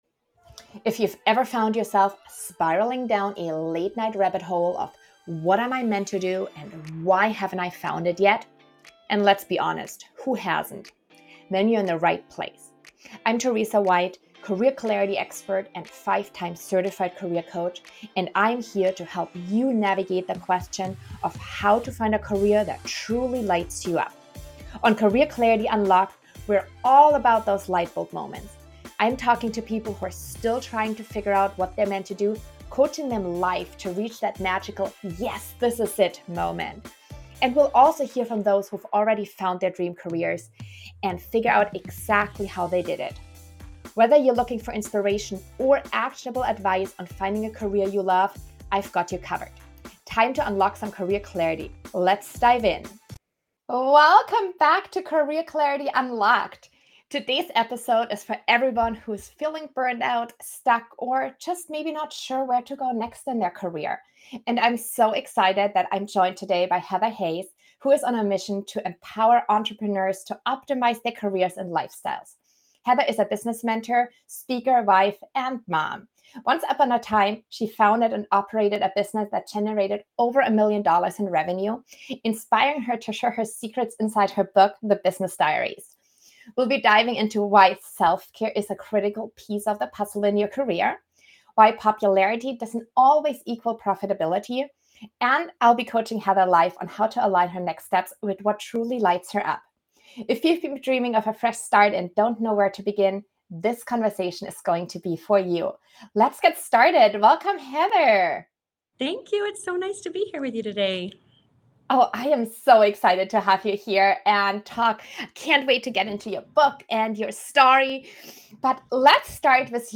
The conversation dives deep into the crucial difference between popularity vs. profitability, self-doubt in business, and what it truly means to adopt an entrepreneurial mindset at any career stage.